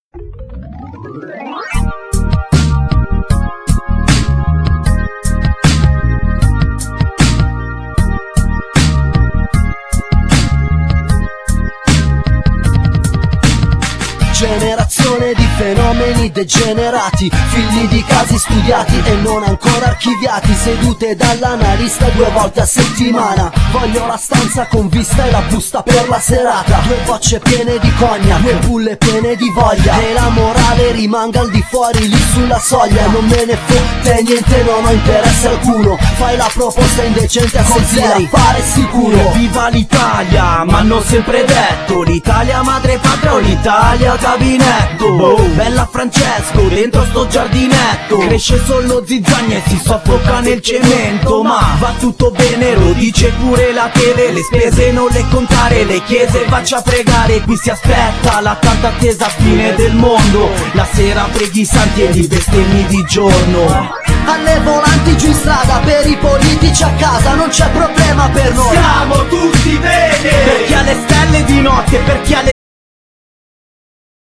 Genere : Punk Rap rock